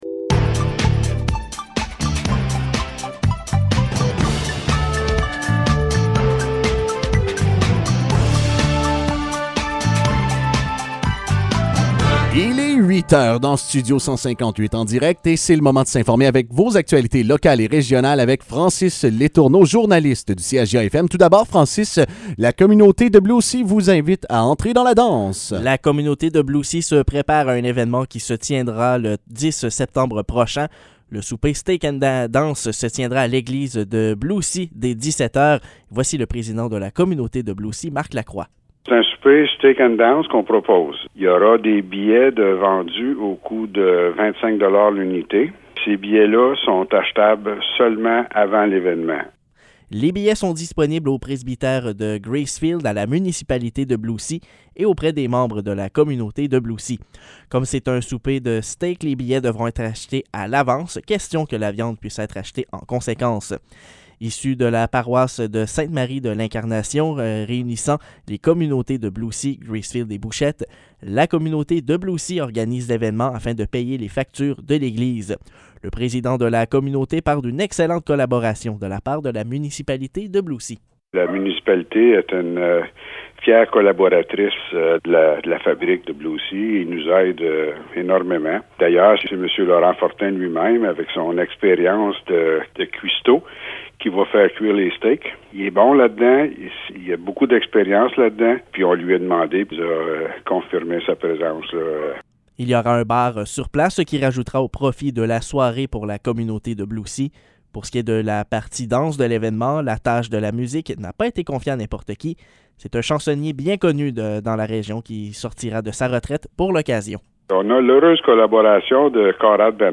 Nouvelles locales - 16 août 2022 - 8 h